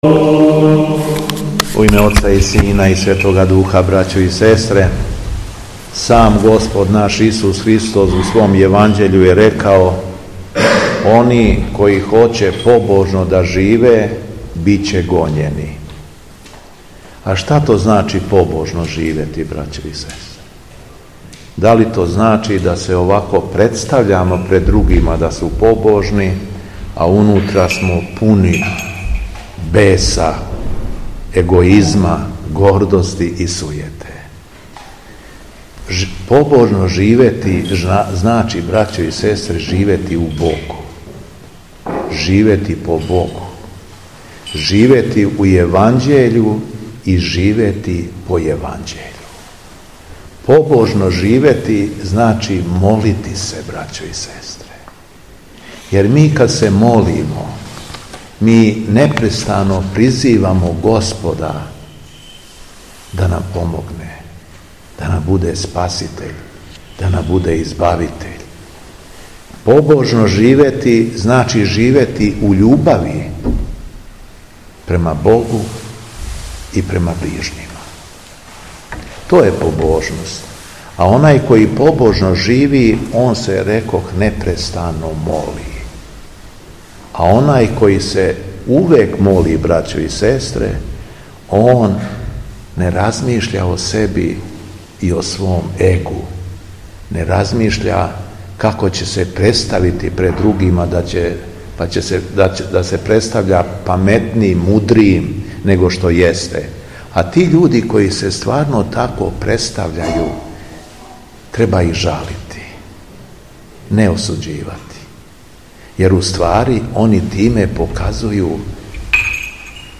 Беседа Његовог Високопреосвештенства Митрополита шумадијског г. Јована
Дана 9. новембра 2024. године, када наша Света Црква прославља Светог мученика Нестора, Његово Високопреосвештенство Митрополит шумадијски Господин Јован, служио је Свету архијерејску литургију у храму Светог апостола Марка у Стојнику у намесништву космајском.